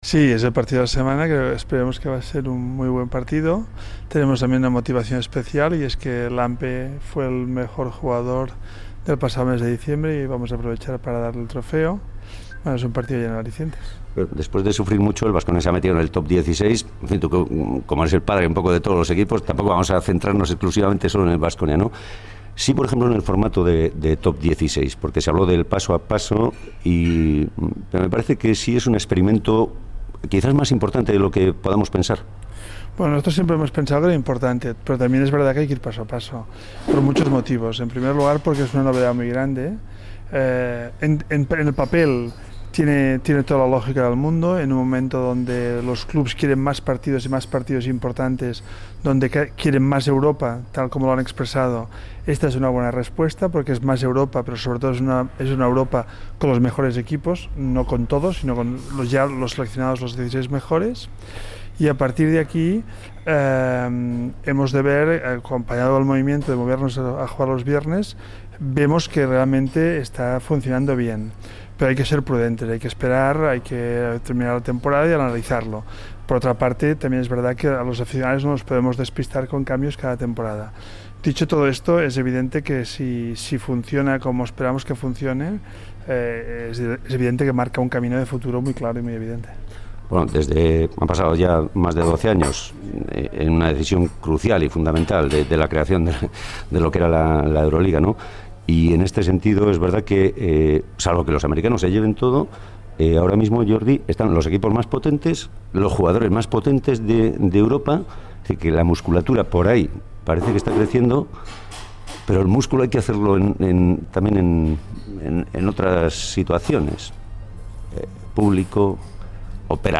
Entrevista a Jordi Bertomeu, máximo responsable de Euroliga